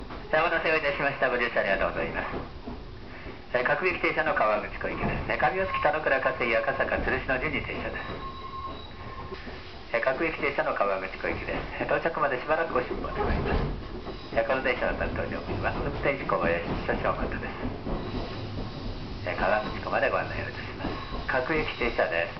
japanese_ann01_announcement.mp3